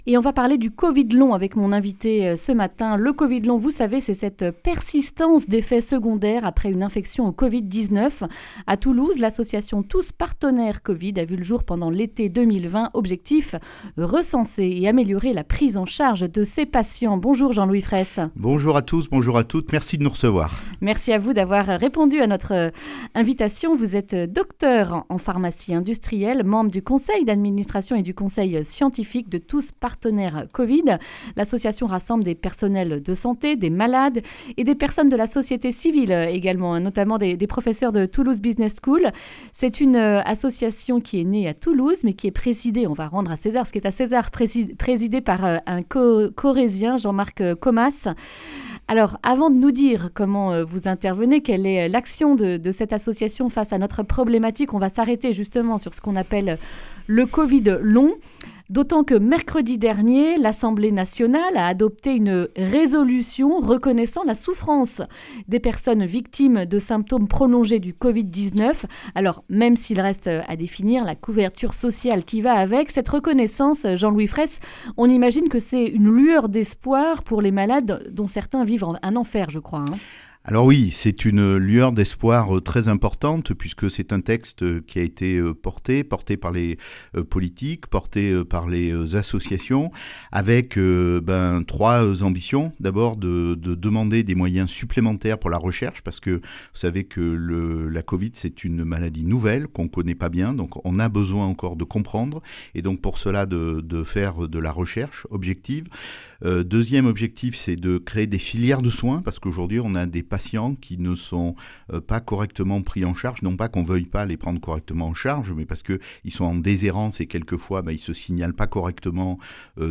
Accueil \ Emissions \ Information \ Régionale \ Le grand entretien \ Covid long : une association toulousaine travaille à améliorer la prise en (…)